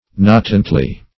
natantly - definition of natantly - synonyms, pronunciation, spelling from Free Dictionary Search Result for " natantly" : The Collaborative International Dictionary of English v.0.48: Natantly \Na"tant*ly\, adv. In a floating manner; swimmingly.